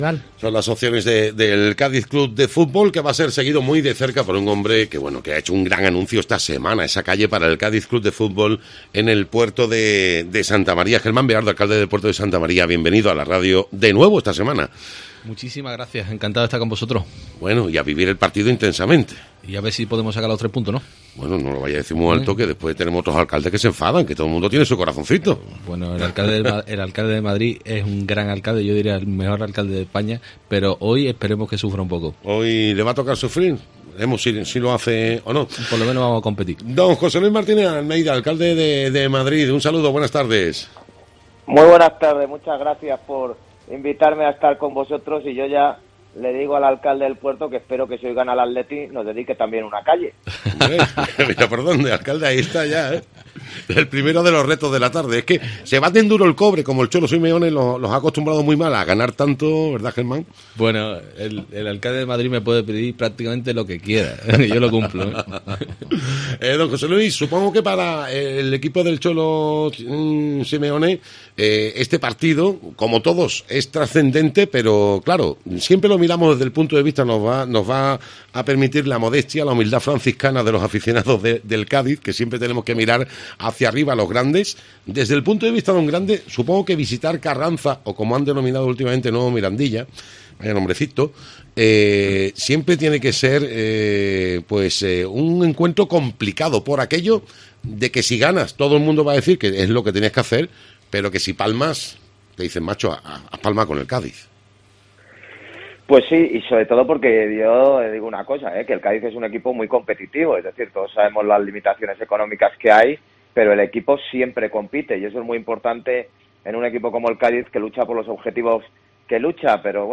Los alcaldes de Madrid y El Puerto analizan las dinámicas de Atlético y Cádiz CF tras el partido del Nuevo Mirandilla en Tiempo de Juego Cádiz
Germán Beardo y José Luis Martínez Almeida en COPE comentando el Cádiz - Atlético
Los ediles de Madrid y El Puerto compartieron retransmisión y ofrecieron sus impresiones durante un partido que acabó con victoria visitante.